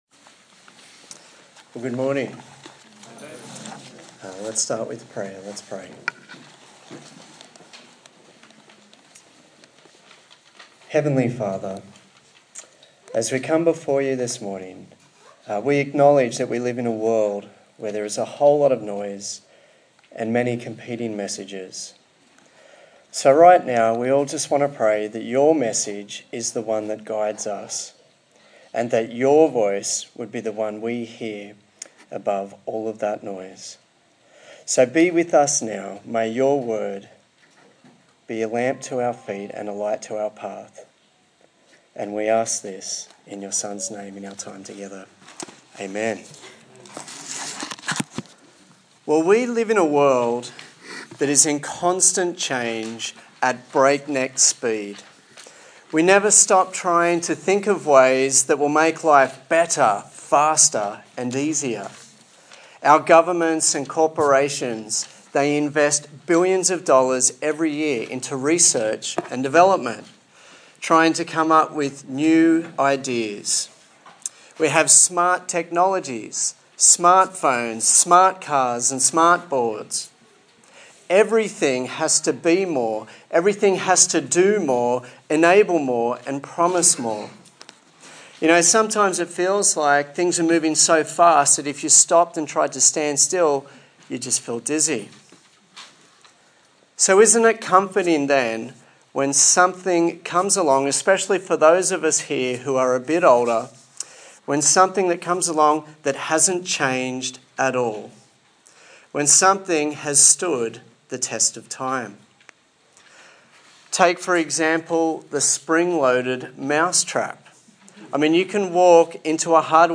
Colossians Passage: Colossians 2:1-15 Service Type: Sunday Morning